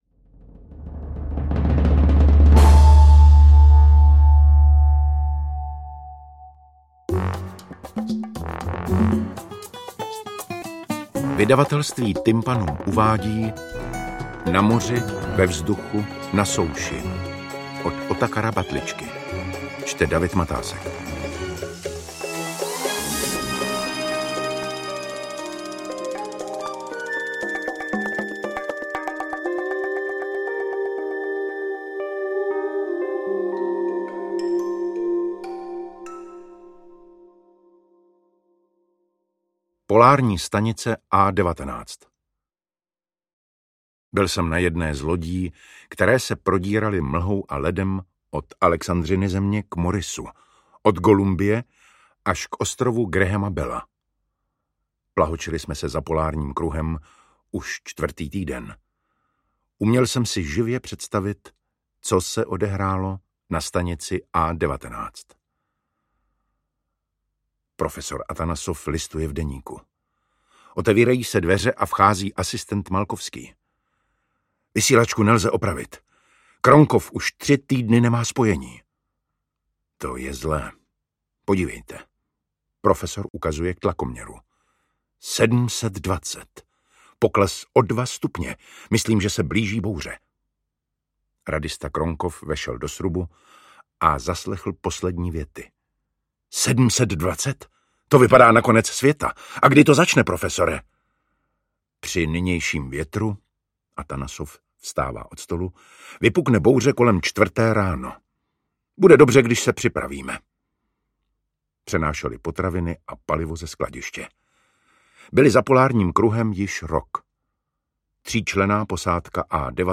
Interpret:  David Matásek
AudioKniha ke stažení, 51 x mp3, délka 7 hod. 5 min., velikost 387,6 MB, česky